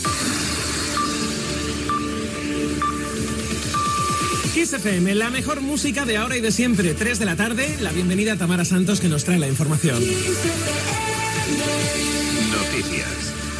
Identificació, hora i dóna pas